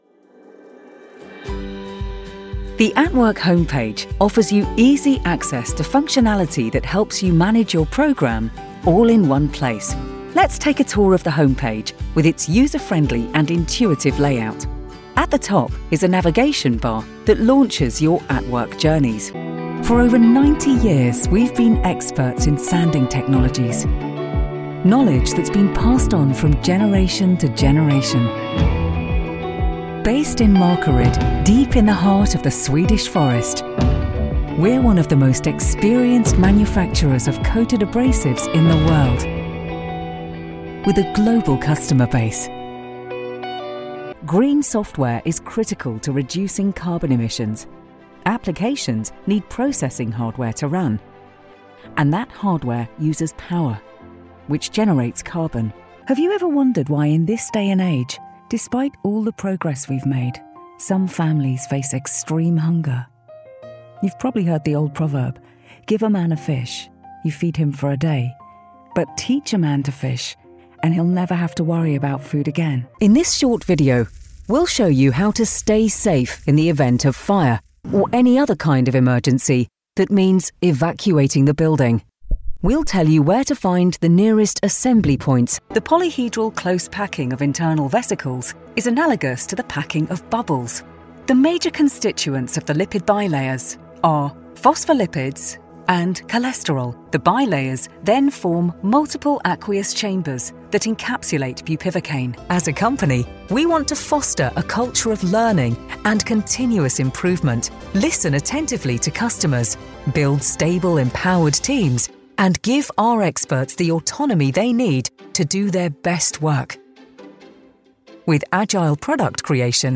Profundo, Natural, Maduro, Amable, Cálida
Corporativo